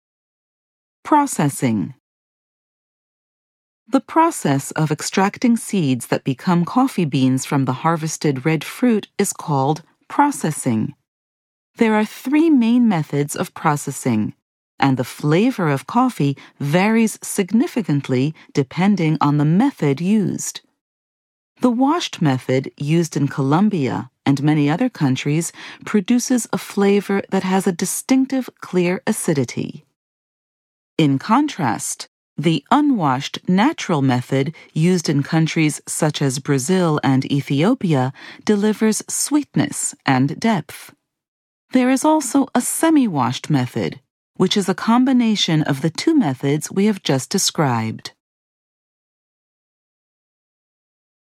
Audio exhibition guide